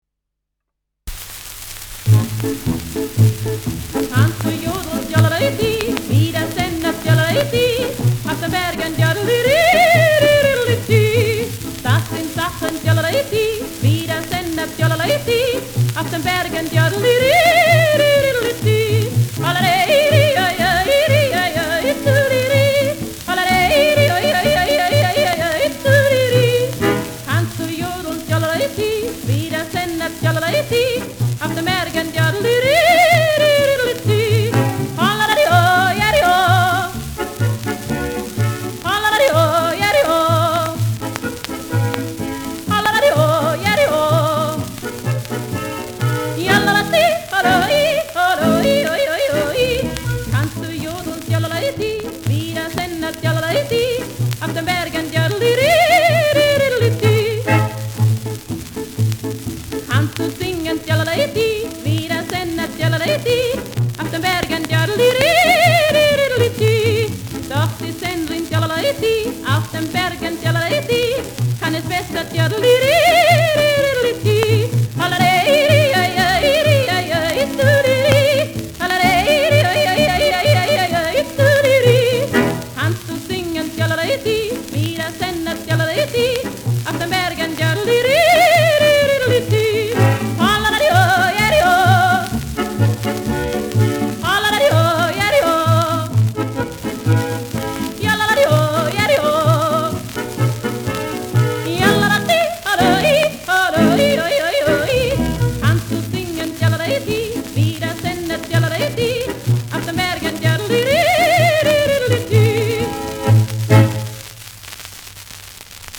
Schellackplatte
leichtes Rauschen
[unbekannte musikalische Begleitung] (Interpretation)